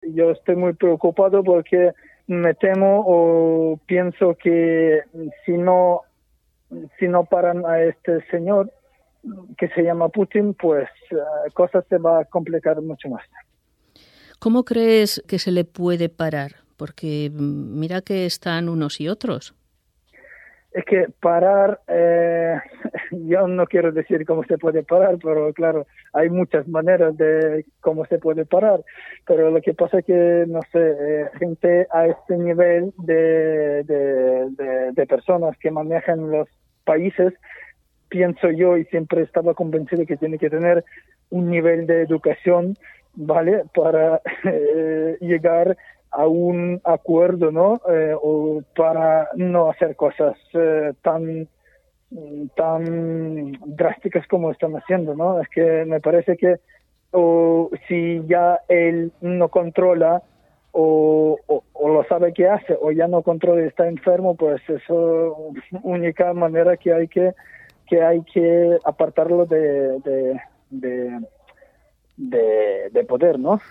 La escalada de violencia no había hecho más que empezar y multiplicarse desde que se realizara esta entrevista, vía teléfono móvil, en la tarde del jueves 10 de marzo.